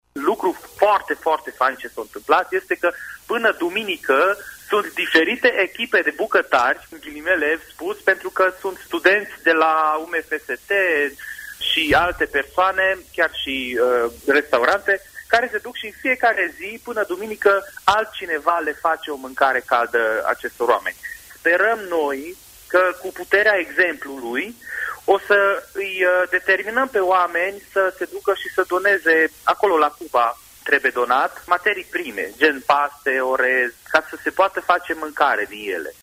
extras din  emisiunea „Bună dimineața, Transilvania!”